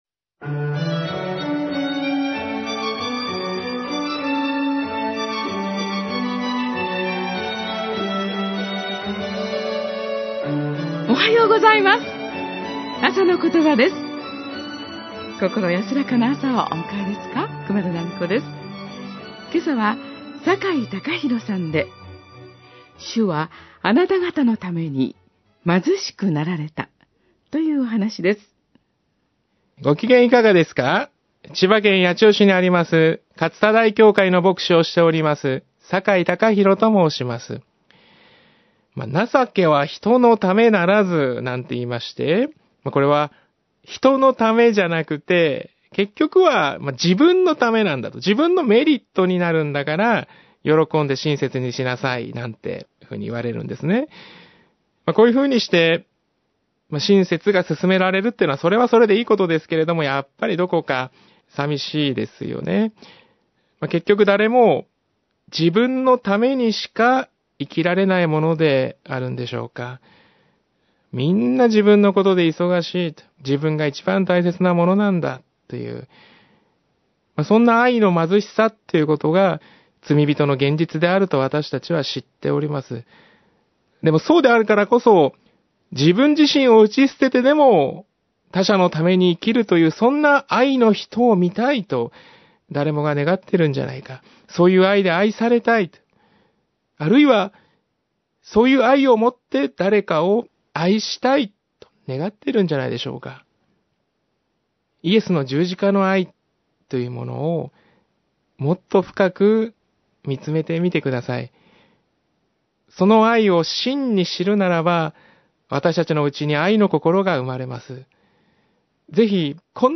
あさのことば　２０１６年１０月１３日（木）放送 　　 あさのことば宛のメールはこちらのフォームから送信ください
メッセージ： 主はあなたがたのために貧しくなられた